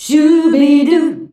SCHUBIDU E.wav